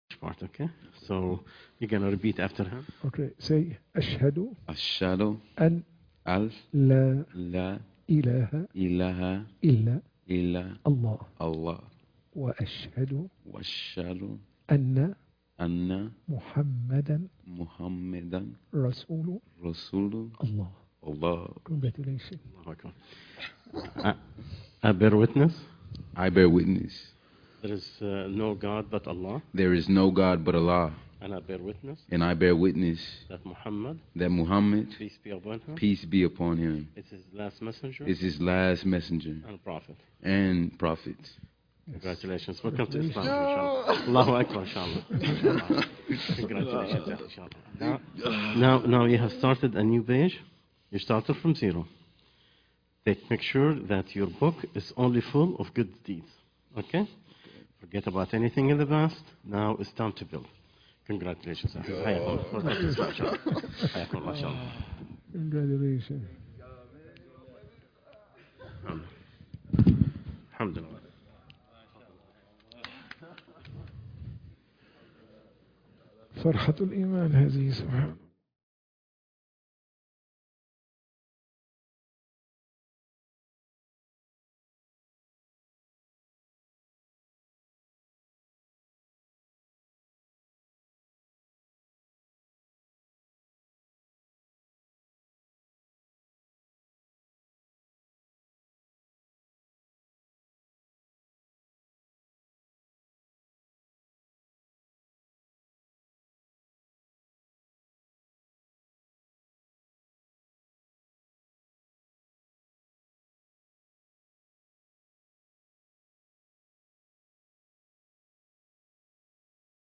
تفسير سورة يونس 11 - المحاضرة 4